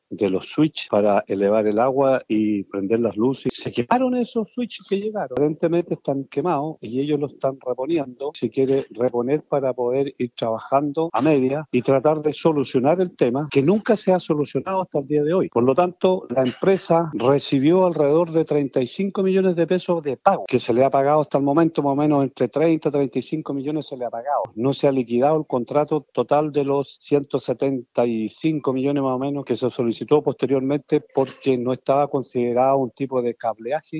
El concejal Juan Carlos Velásquez criticó los problemas técnicos que presenta la estructura, asegurando que la empresa ha hecho reparaciones a medias.
concejal-osorno-pileta.mp3